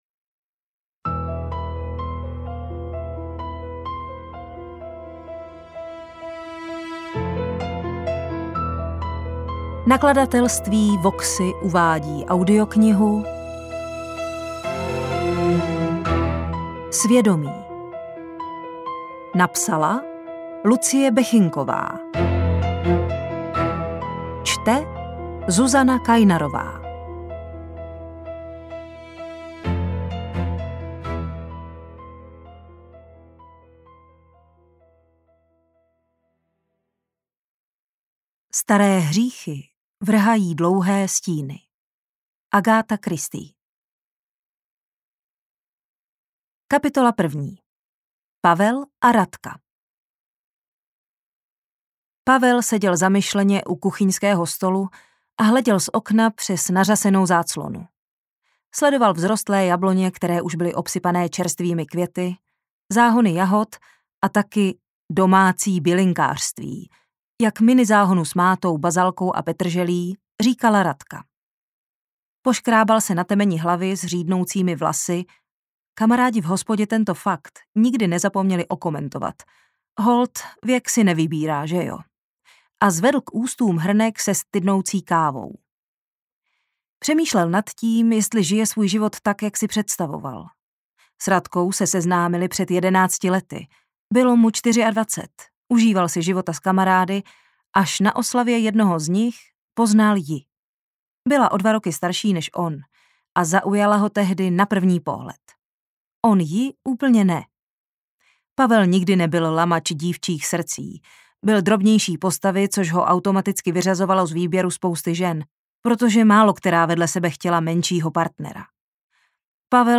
detektivky
AudioKniha ke stažení, 80 x mp3, délka 11 hod. 41 min., velikost 636,0 MB, česky